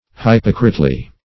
hypocritely - definition of hypocritely - synonyms, pronunciation, spelling from Free Dictionary Search Result for " hypocritely" : The Collaborative International Dictionary of English v.0.48: Hypocritely \Hyp"o*crite*ly\, adv.
hypocritely.mp3